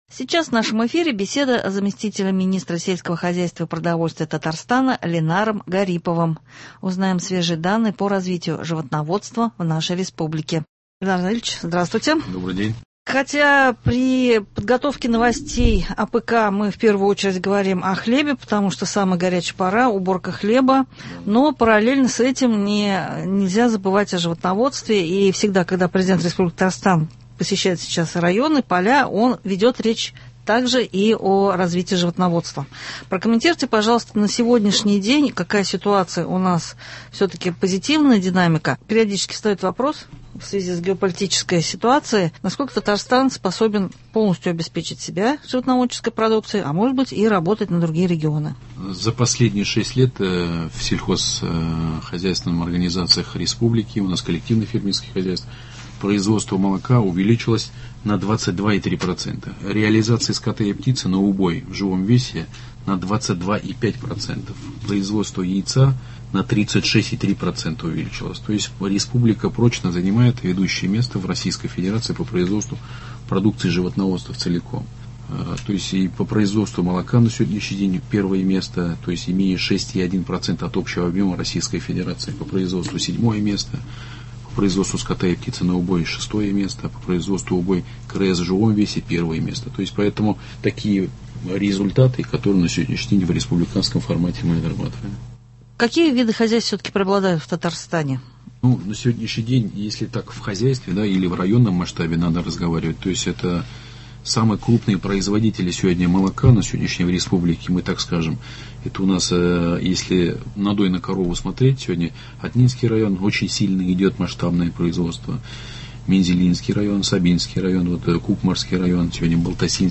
Актуальные вопросы развития Татарстана: Беседа с Заместителем министра сельского хозяйства и продовольствия РТ Ленаром Гариповым- узнаем свежие данные